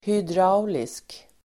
Ladda ner uttalet
hydraulisk adjektiv, hydraulic Uttal: [hydr'a_olisk] Böjningar: hydrauliskt, hydrauliska Definition: som drivs med vätsketryck (operated or effected by means of a liquid) Exempel: hydrauliska bromsar (hydraulic brakes)